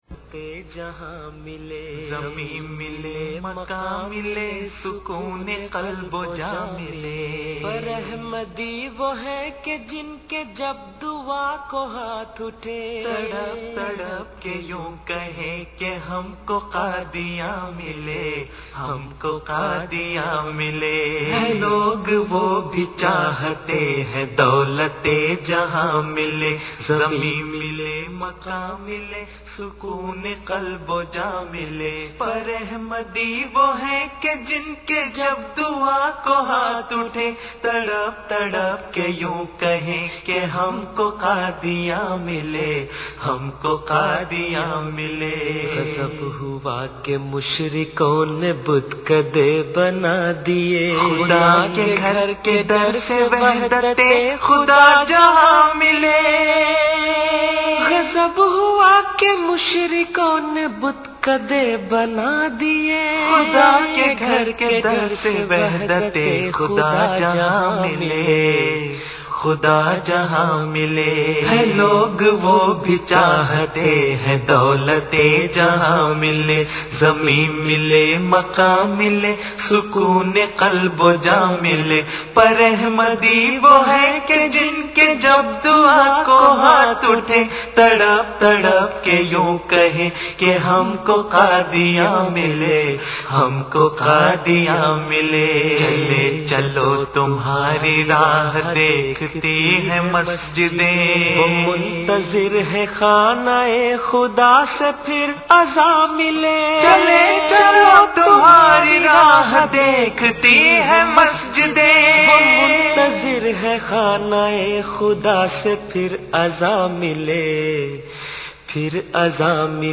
Urdu Poems
Jalsa Salana Qadian 2005